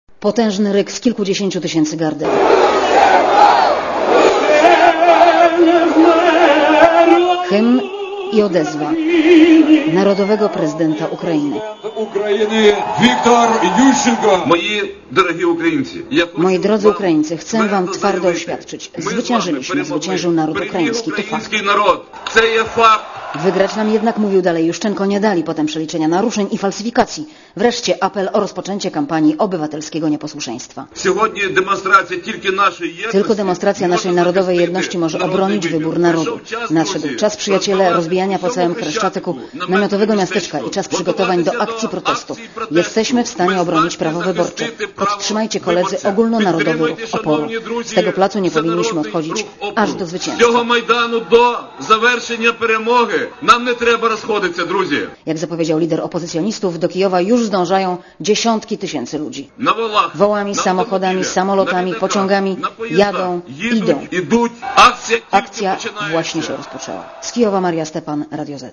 ukraina-wielki_wiec.mp3